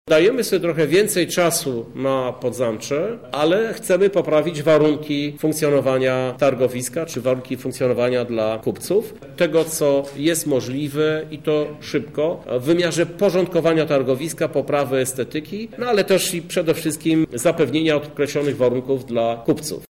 Nowe Targowisko – mówi Krzysztof Żuk, prezydent Lublina